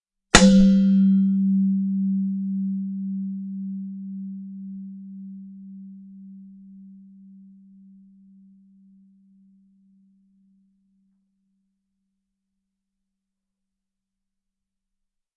铃铛/锣鼓 " 不锈钢碗 4
描述：一个不锈钢碗，用一个木制的敲击器敲击，
Tag: 打击乐器 不锈钢